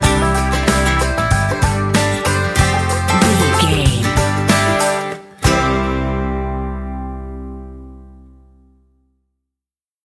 Ionian/Major
acoustic guitar
banjo
bass guitar
drums
Pop Country
country rock
bluegrass
uplifting
driving
high energy